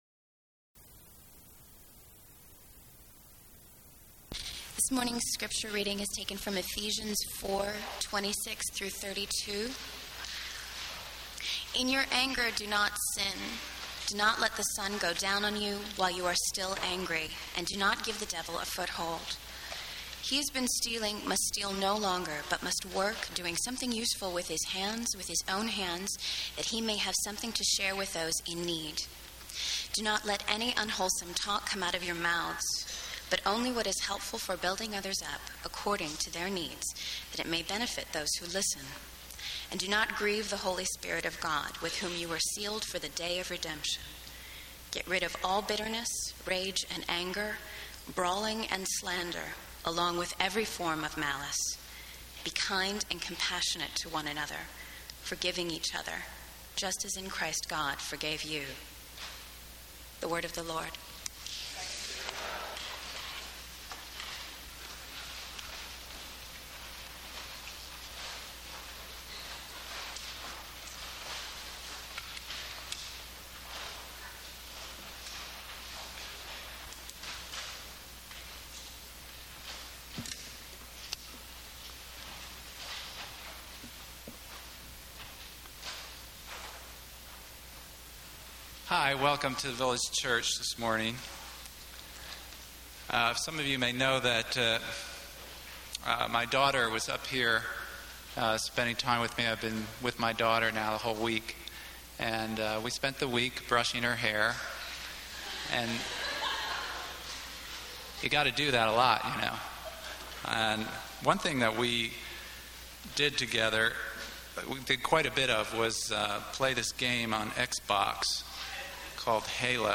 There are other things to say about the process of forgiveness, of taking time, of the matter of truly serious betrayal, etc. But this sermon gets at the heart of what God wants for us.